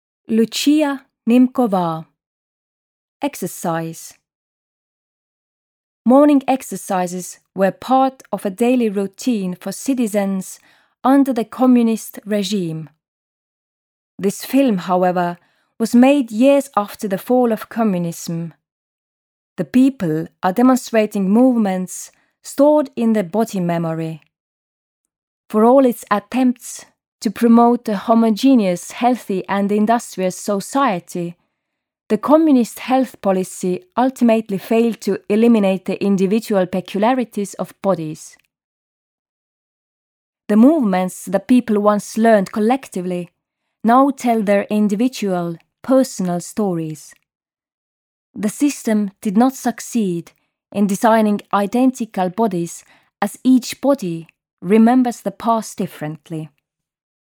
Audioguide for the exhibition Archaeologists of Memory: Vitols Contemporary Art Collection
Audio guide no 17